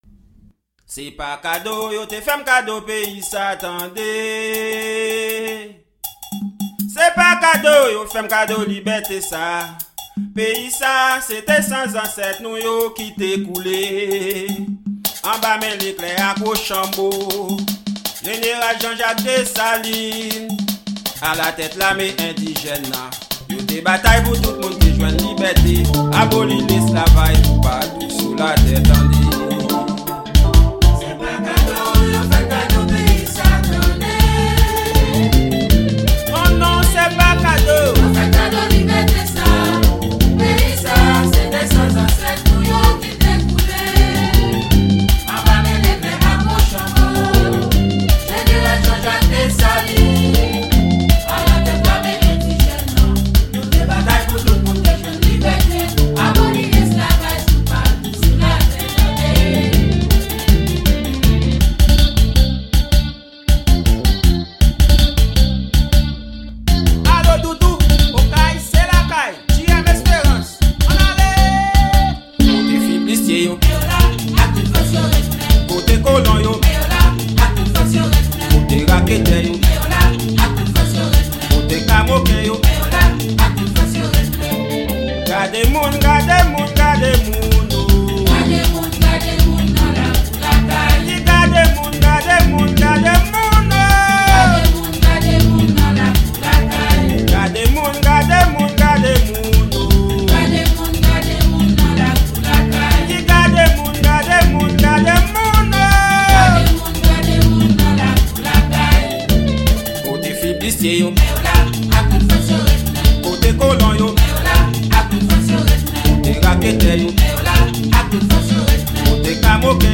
Genre: Rasin.